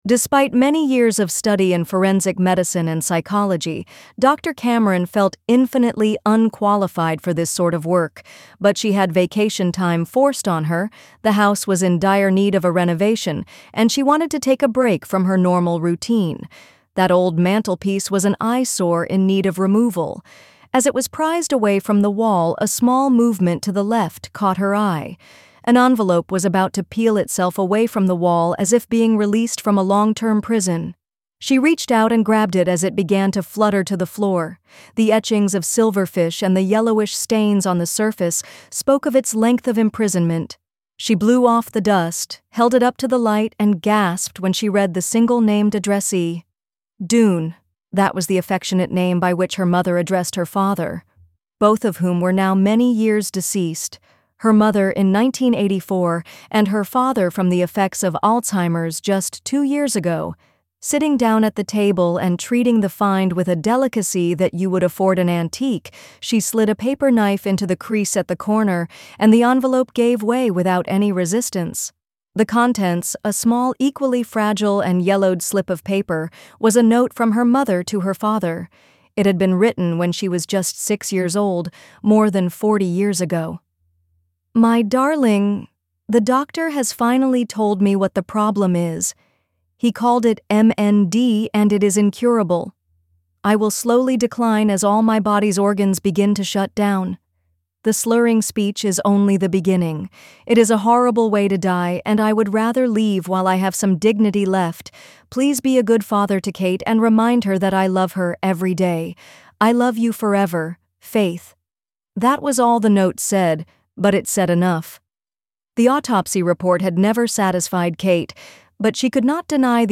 (Short story 450 words)   When a forced renovation uncovers a long-lost letter behind an old mantelpiece, forensic psychologist Dr. Kate Cameron finally uncovers the truth behind her mother’s mysterious death.